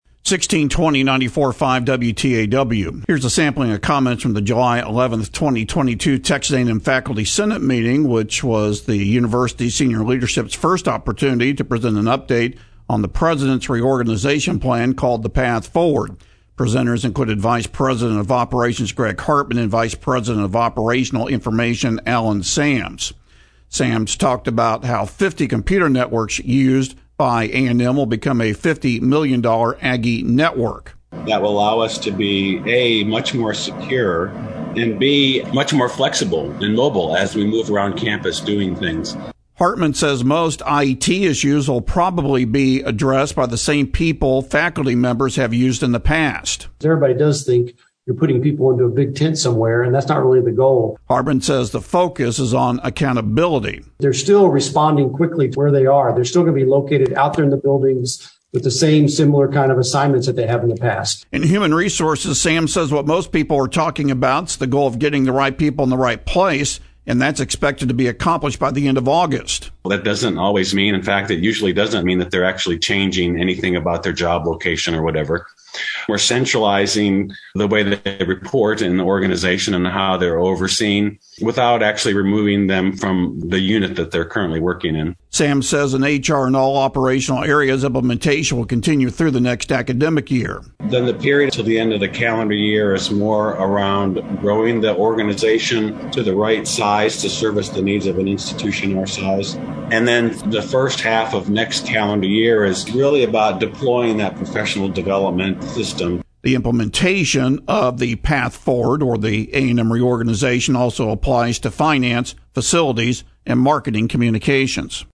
Texas A&M Administrator's First Update On Implementing "The Path Forward" Is Given To The Faculty Senate - WTAW | 1620AM & 94.5FM